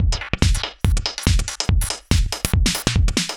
Index of /musicradar/uk-garage-samples/142bpm Lines n Loops/Beats
GA_BeatAFilter142-05.wav